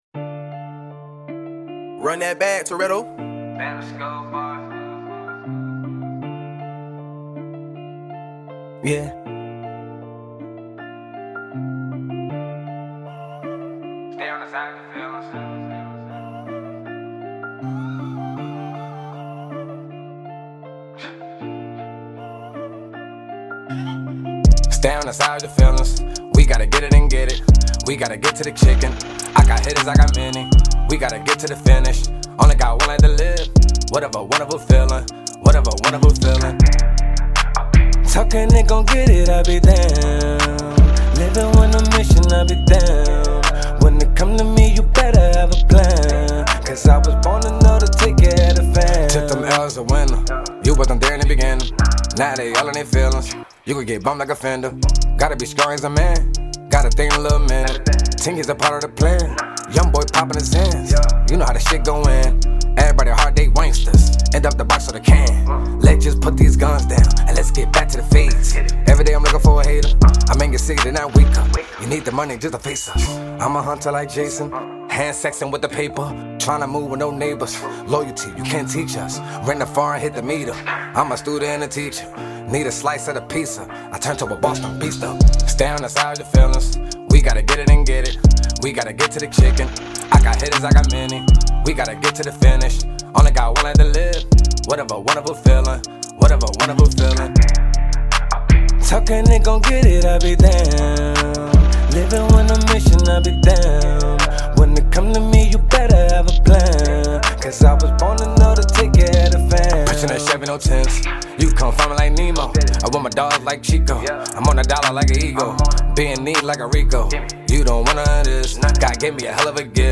Rap Artist